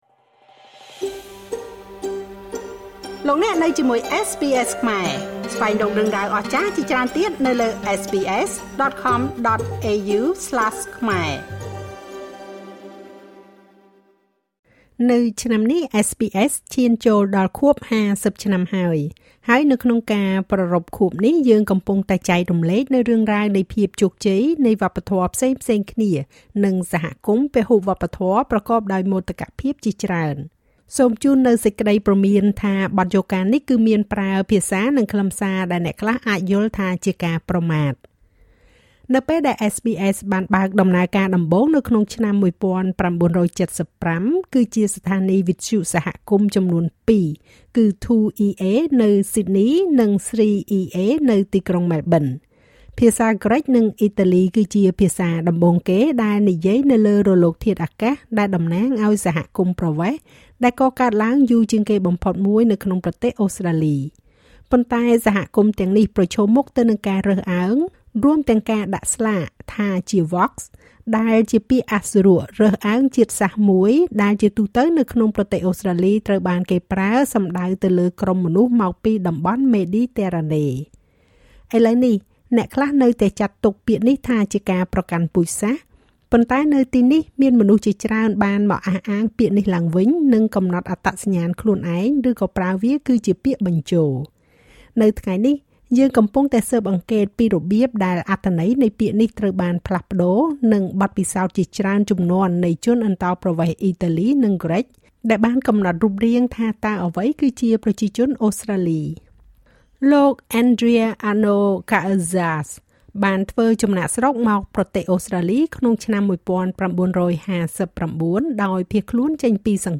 These Greek and Italian migrants share their experiences of Australia and their place within it.